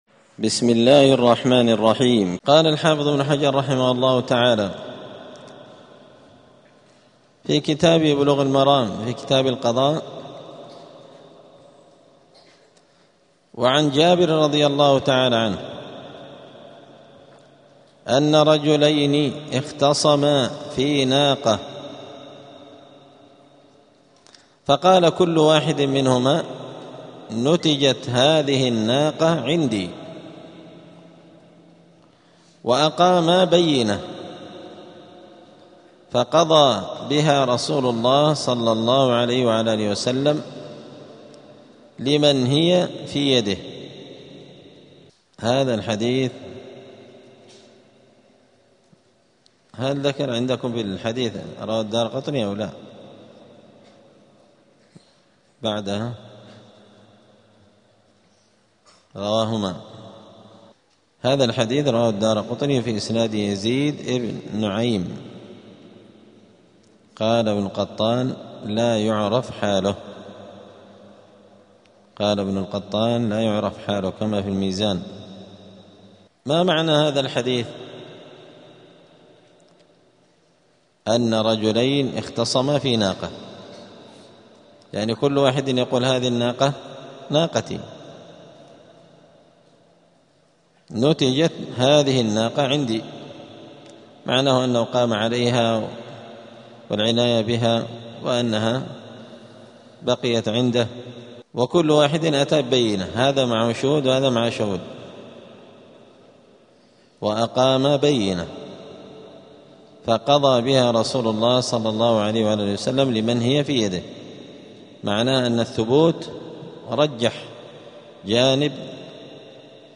*الدرس السادس والعشرون (26) {اﻟﻴﺪ ﻣﺮﺟﺤﺔ ﻟﻠﺸﻬﺎﺩﺓ اﻟﻤﻮاﻓﻘﺔ ﻟﻬﺎ}*
دار الحديث السلفية بمسجد الفرقان قشن المهرة اليمن